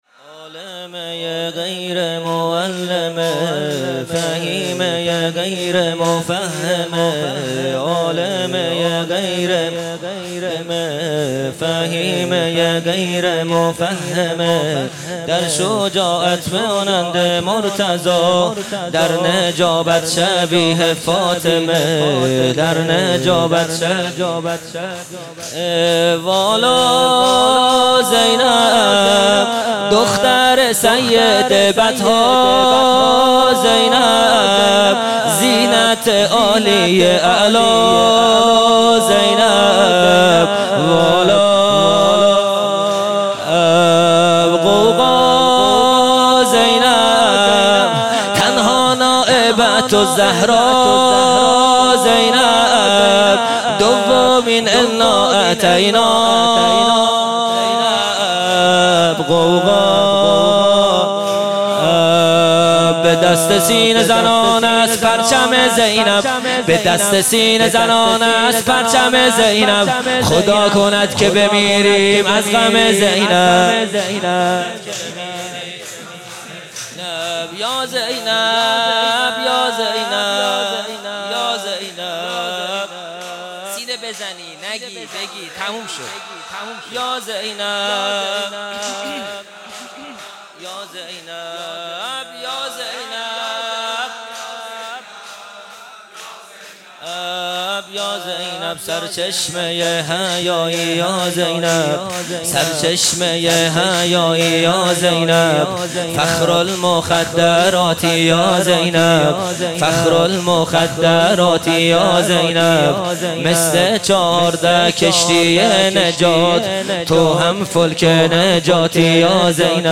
خیمه گاه - هیئت بچه های فاطمه (س) - شور | عالمۀ غیر معلمه | 27 آبان 1400
جلسه هفتگی